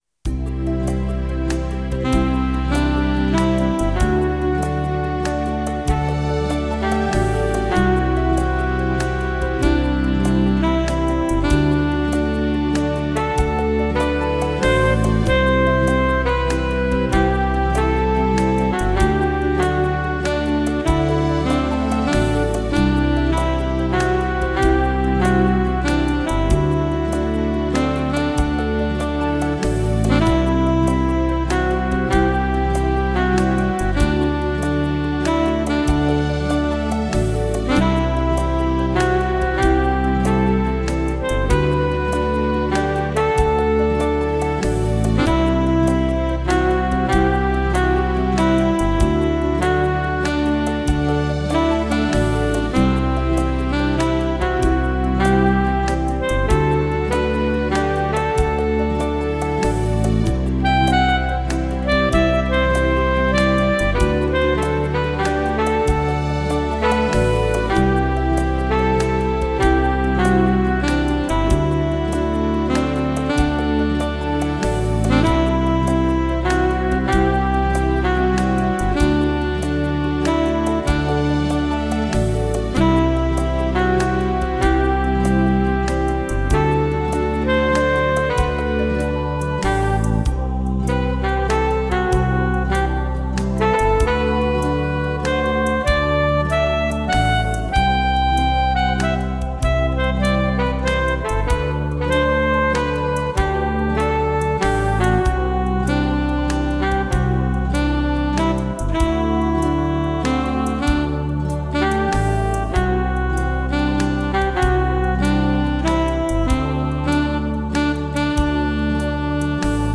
Tempo: 35 bpm / Datum: 13.06.2017